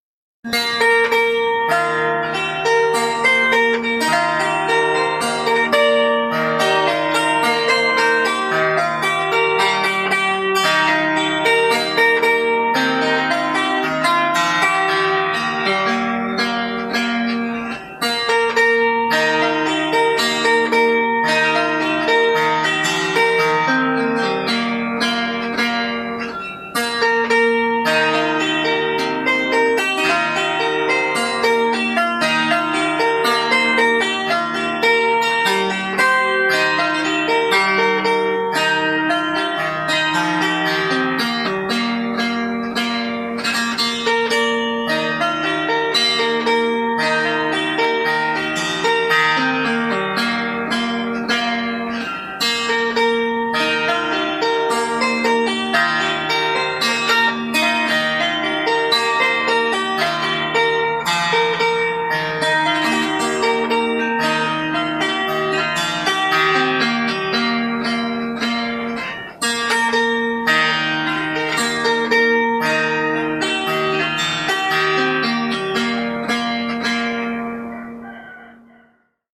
гөсләдә "Фазыл чишмәсе